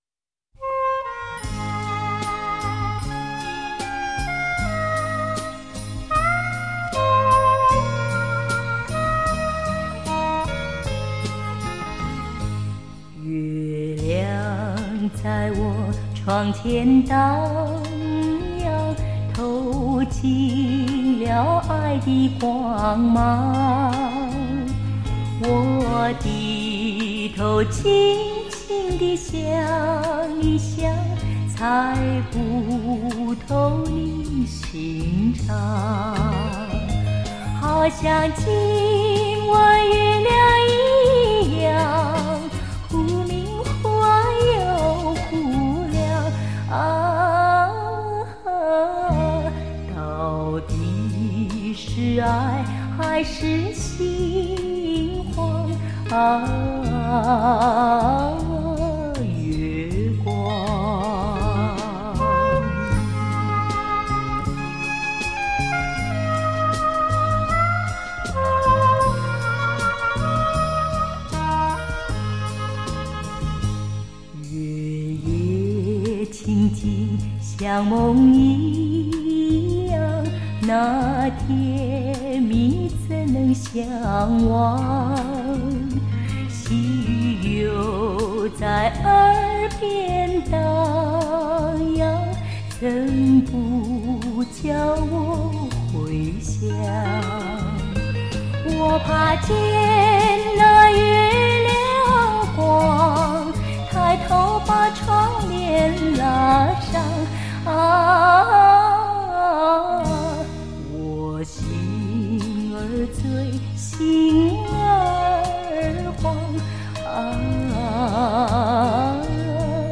女歌手